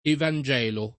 evangelo [ evan J$ lo ]